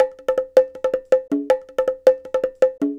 Bongo 10.wav